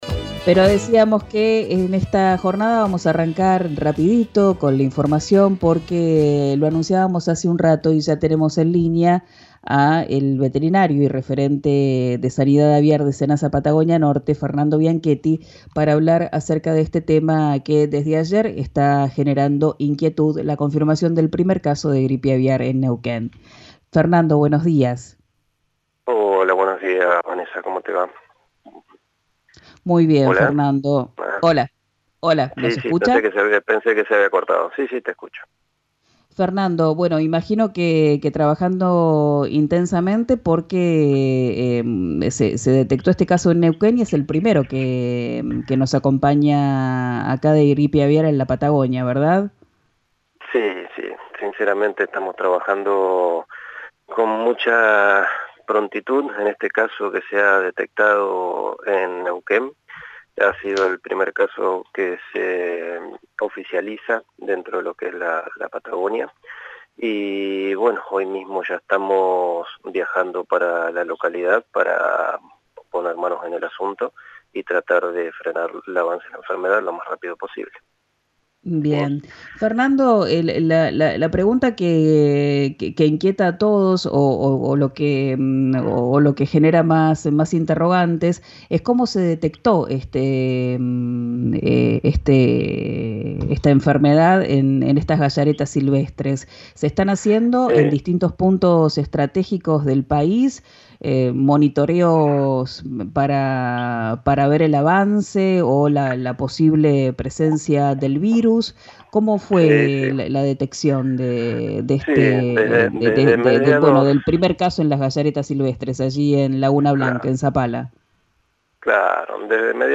En declaraciones a Quién dijo verano, por RÍO NEGRO RADIO